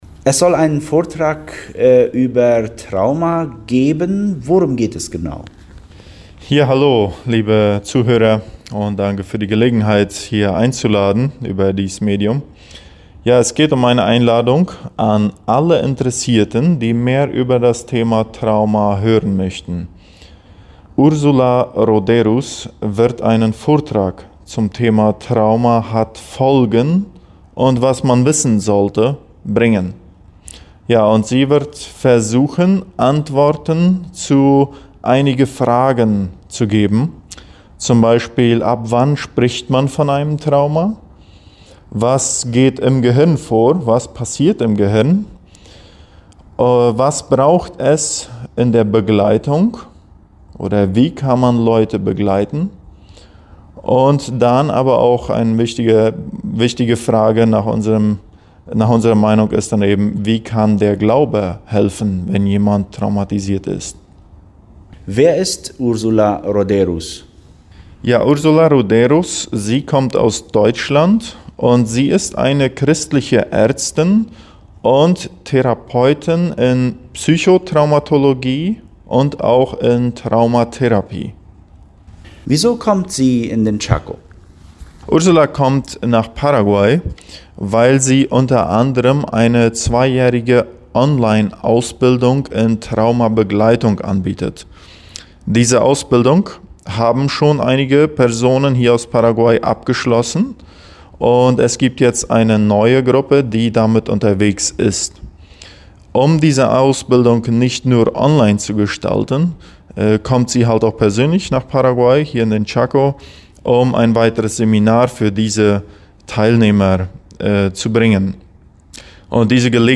Vortrag Trauma